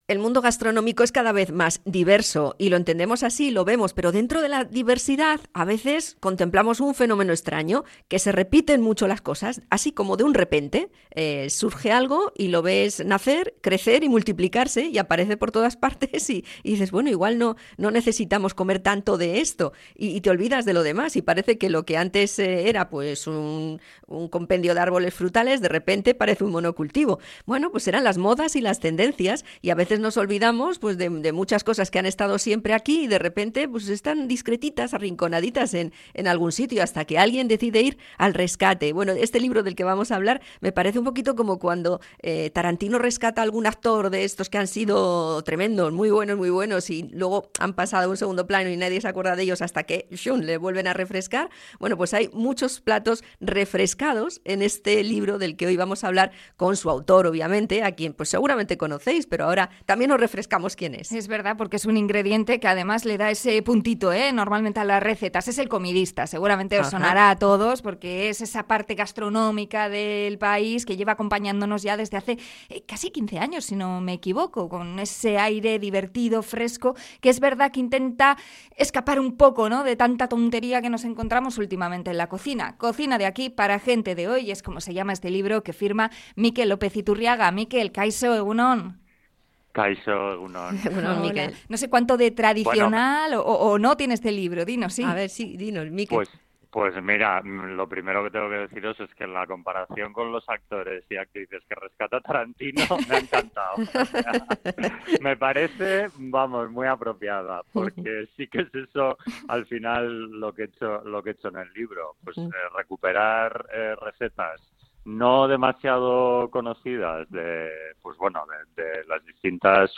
Entrevista a Mikel López Iturriaga, el 'Comidista'